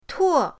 tuo4.mp3